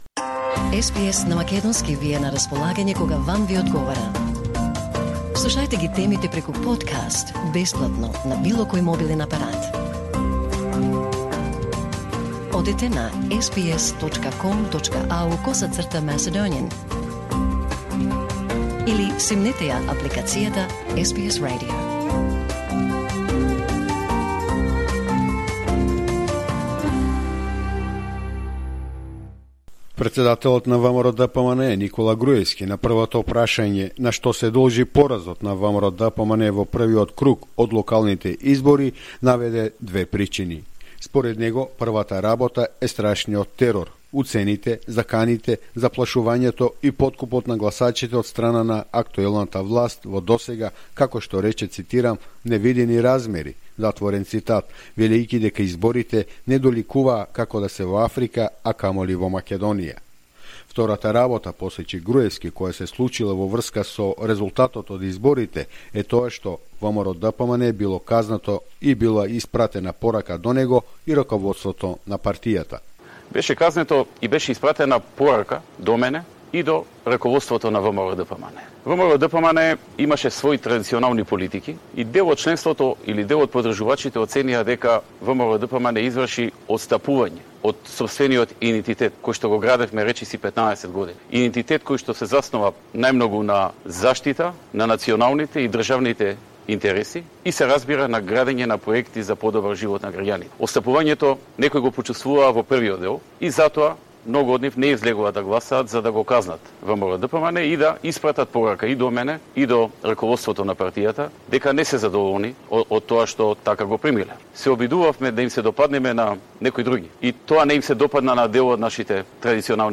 In an interview for TV Alfa, Nikola Gruevski has accused the current government for the badly organized local elections. He says he accepts responsibility for the defeat as well as voter sentiment.
intervju_nikola_gruevski_-_gotovo_1.mp3